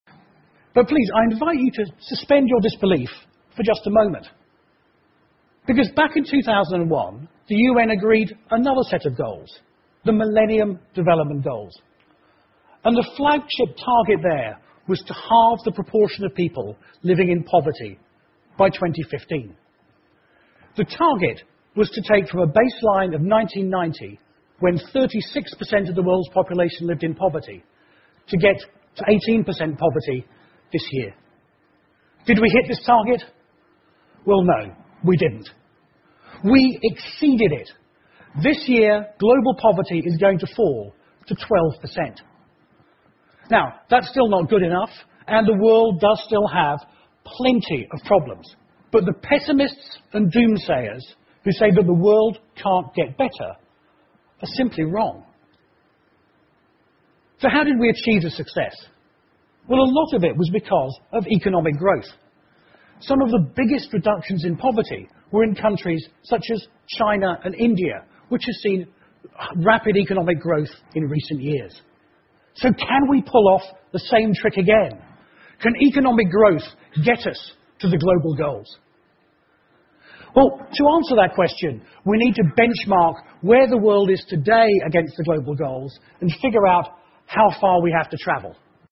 TED演讲:如何让世界到2030年时变得更好() 听力文件下载—在线英语听力室